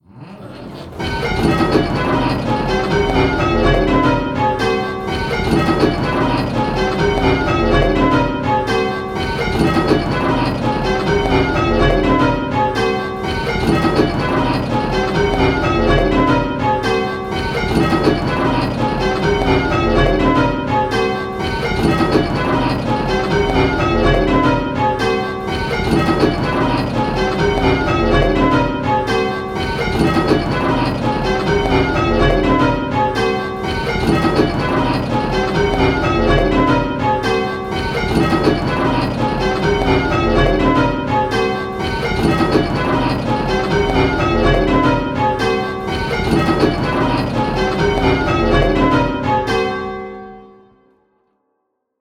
Hearing 8 bells...
We know that hearing specific bells can be difficult, so in these clips we have kept it very simple… in each clip 1 bell is ringing early… and consistently at both hand and backstroke.
Click the play button and listen to the rhythm of the bells…. the rhythm will sound a bit lumpy!
Rounds-8-6E.m4a